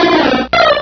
sovereignx/sound/direct_sound_samples/cries/spearow.aif at master